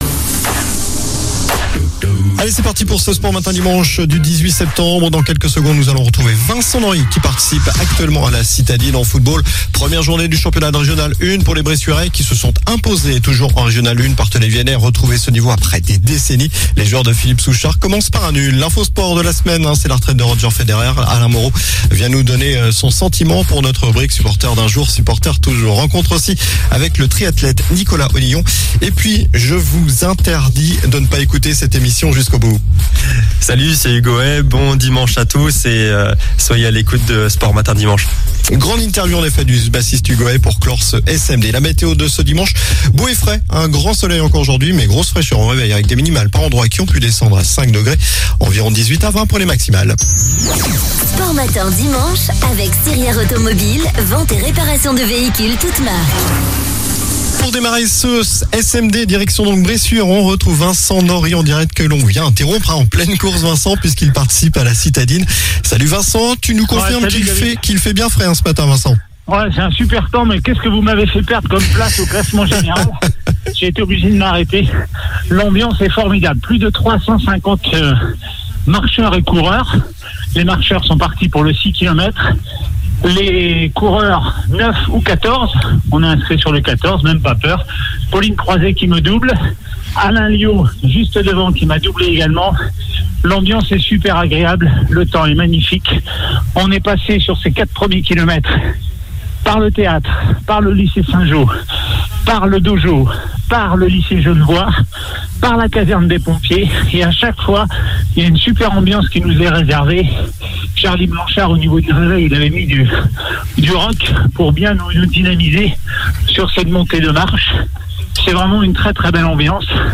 en direct de la course à pied La Citadine